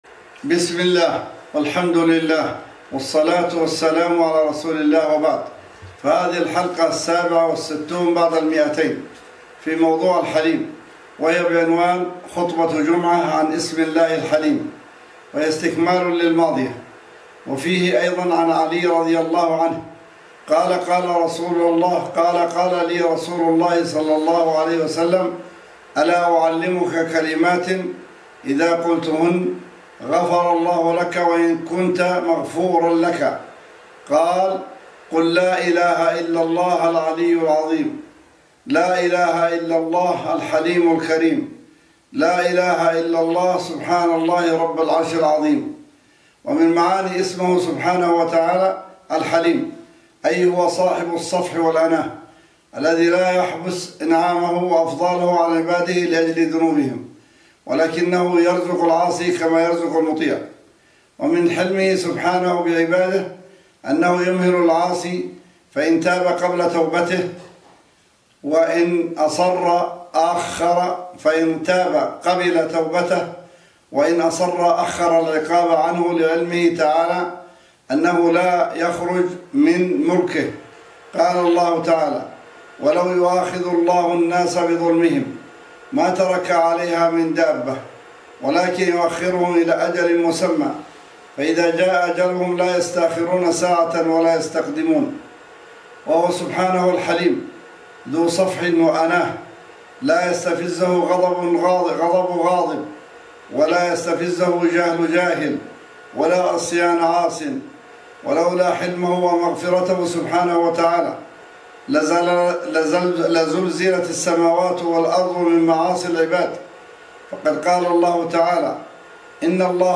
بعنوان: خطبة جمعة عن اسم الله (الحَلِيم) :